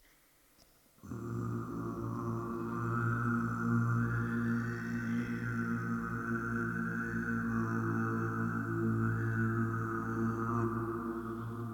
kargyraa Throat Singing
chant false-chord kargyraa meditation throat-singing Tibetan sound effect free sound royalty free Voices